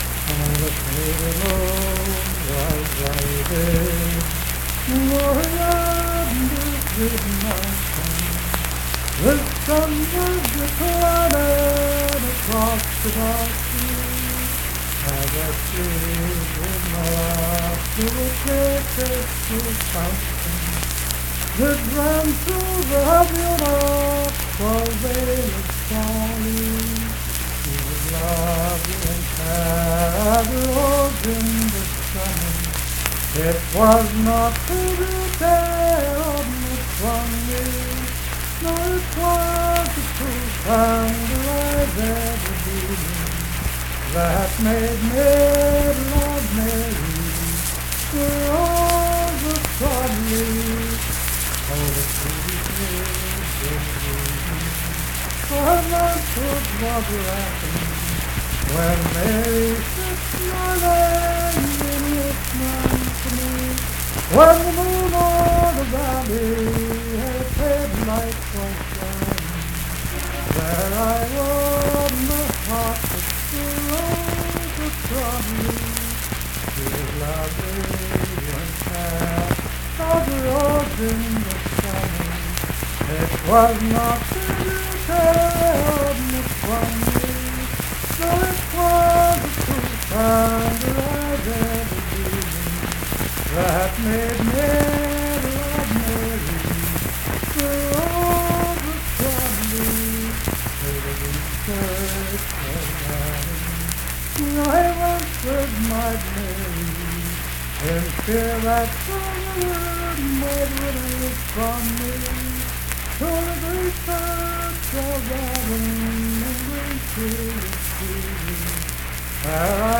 Unaccompanied vocal music
Richwood, Nicholas County, WV.
Voice (sung)